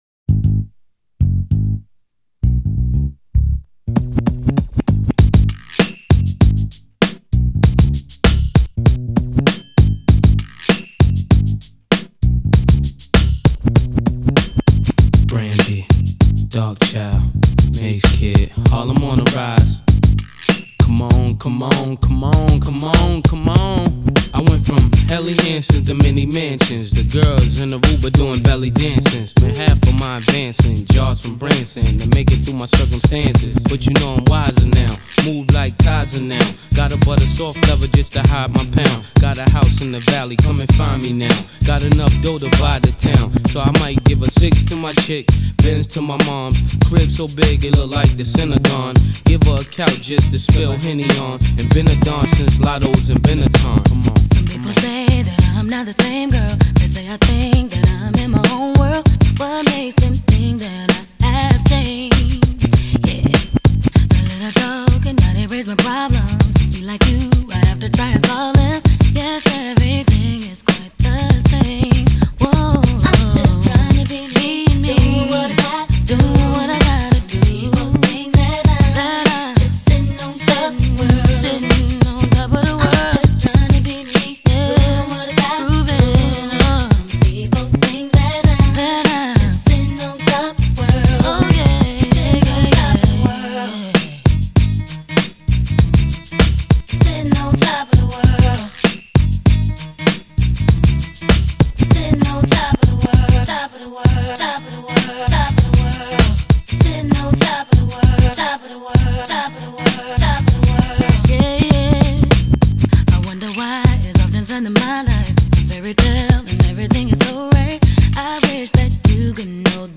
отражает чувство уверенности, радости и успеха